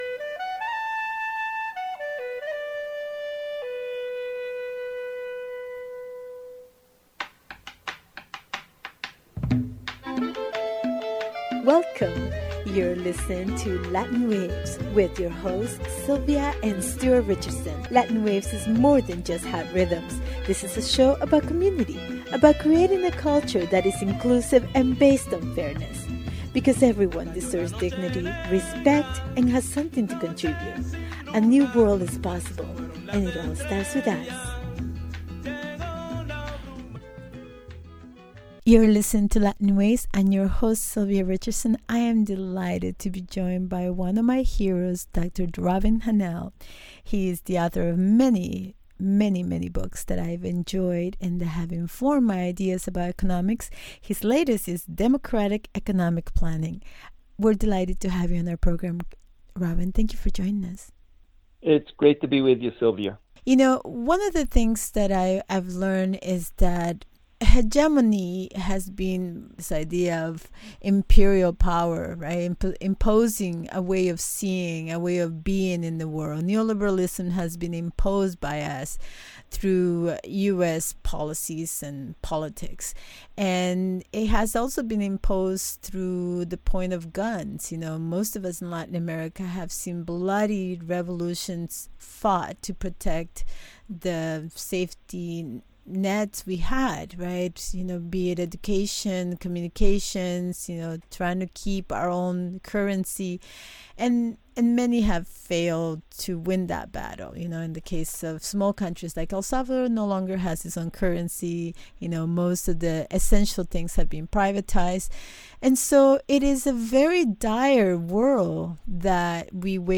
Interview
Mono